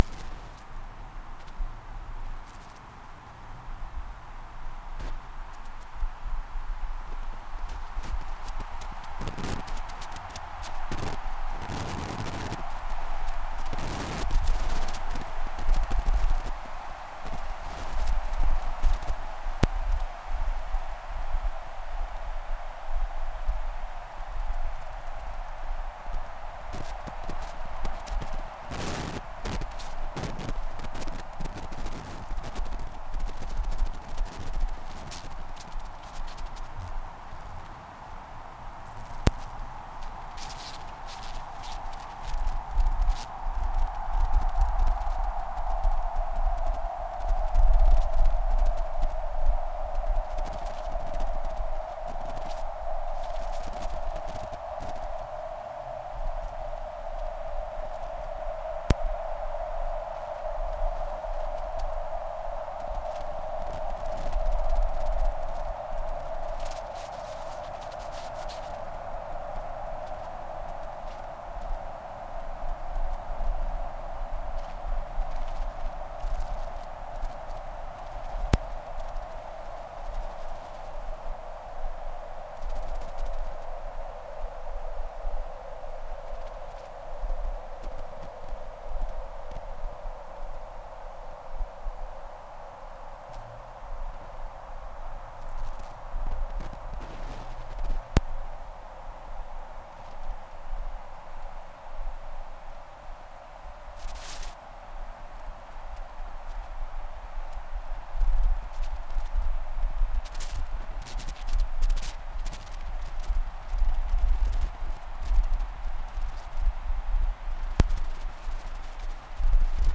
Bilim insanlarının gönderdiği araştırma balonu, atmosfer tabakalarından stratosfer tabakasında gözlem yaparken olağan dışı sesler raporladı. Yerin yetmiş bin feet üzerinde kaydedilen bu sesler, stratosfer tabakasının normal boğuk sesinden ayrışarak cızırtılı bir şekilde duyuluyor.